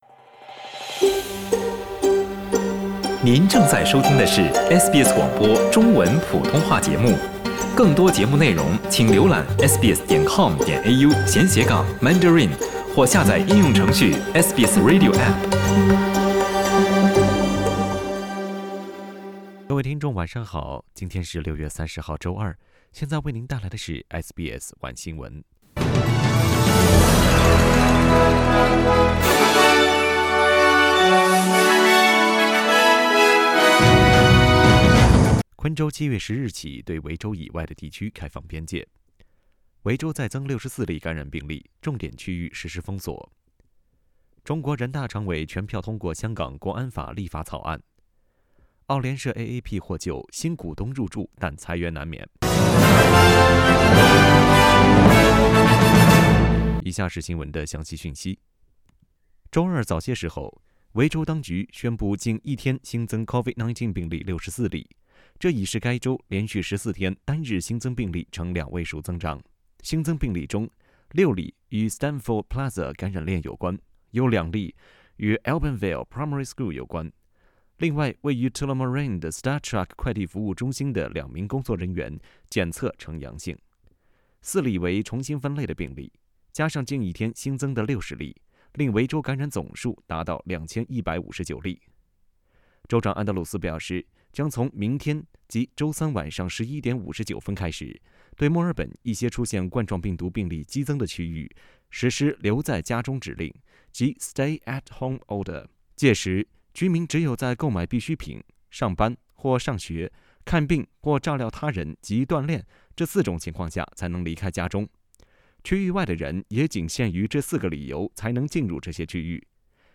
SBS晚新闻（6月30日）
mandarin_news_3006.mp3